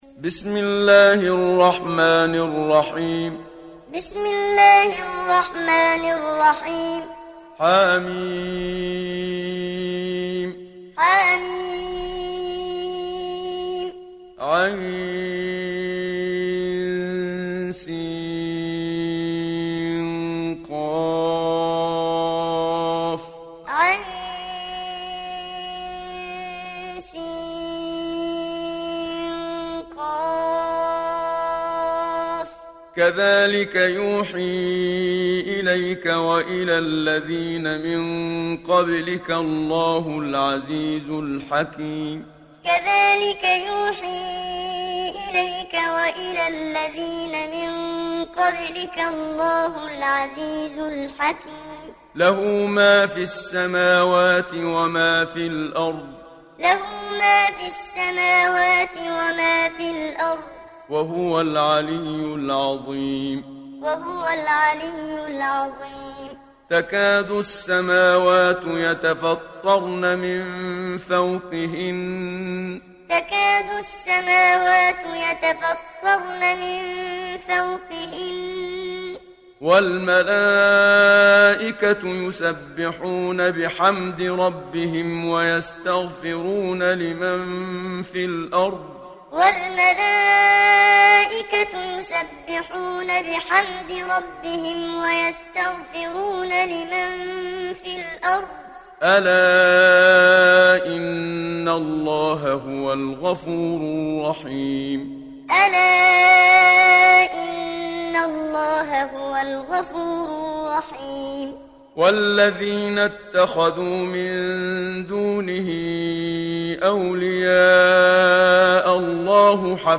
دانلود سوره الشورى محمد صديق المنشاوي معلم